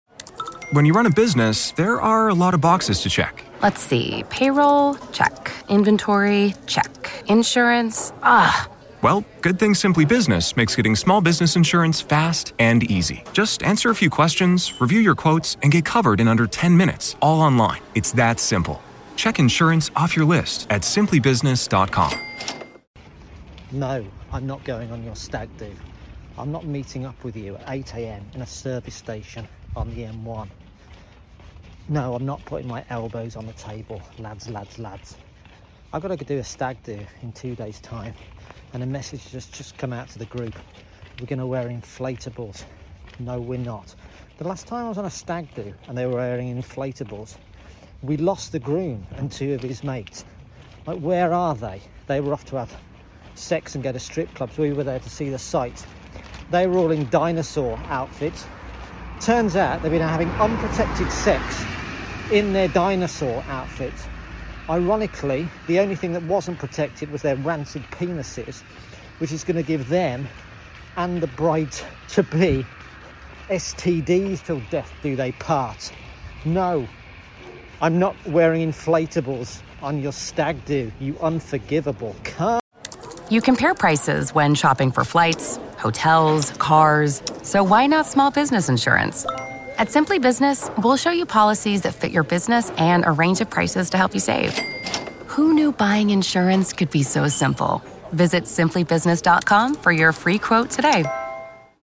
Season 4 is a limited season of shorter bits mainly without piano